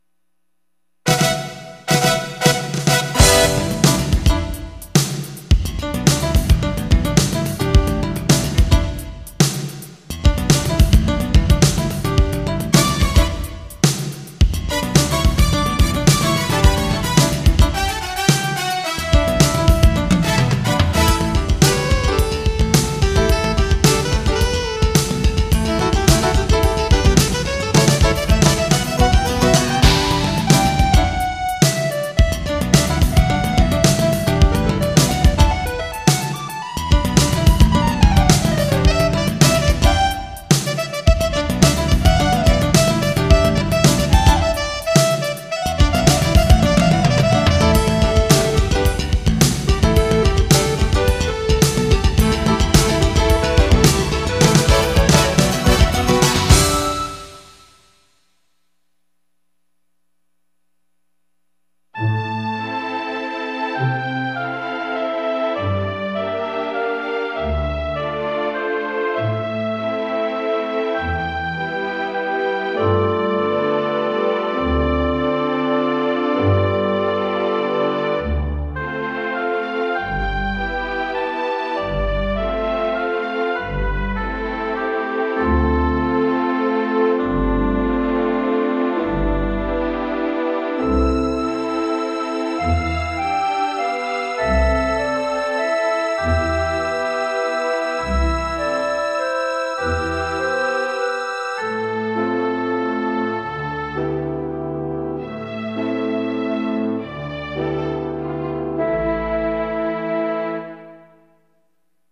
Ensoniq Soundscape Elite Sound Card